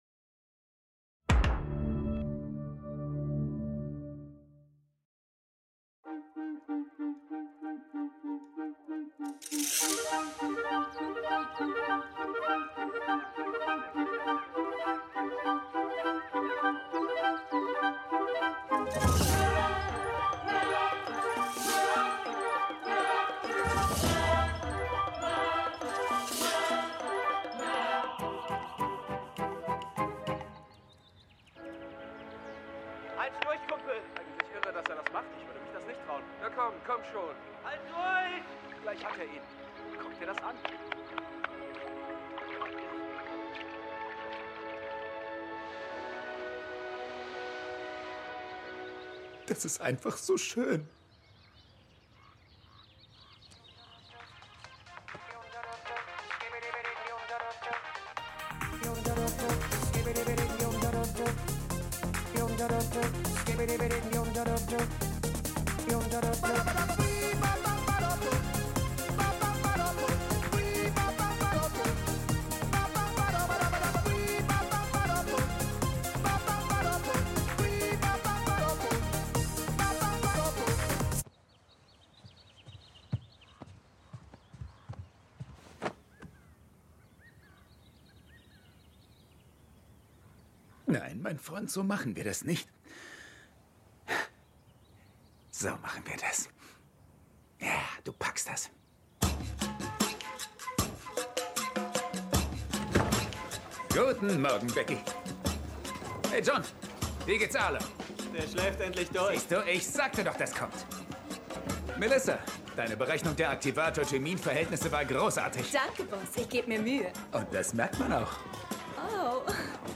Instabil - Unstable Hörspiel